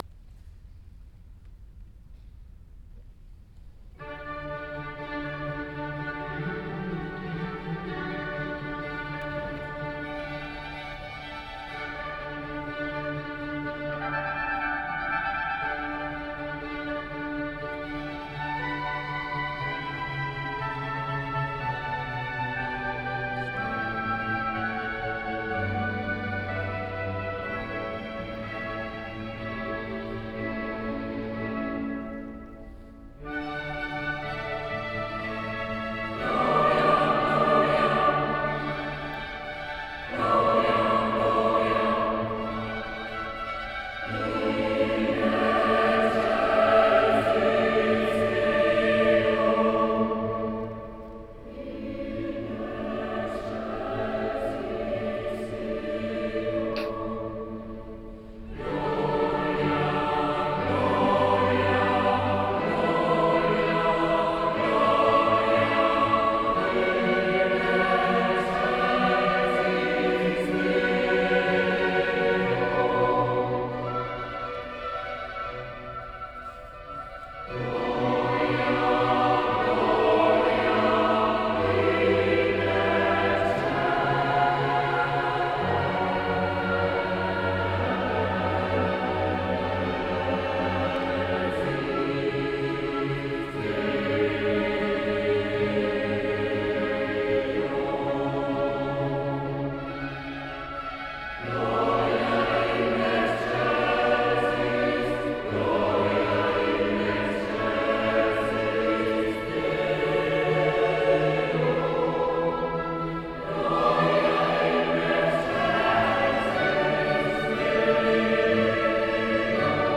Diese sind Mitschnitte aus verschiedenen Konzerten.
aufgeführt am 9. Dezember 2006 in der St. Bartholomäuskirche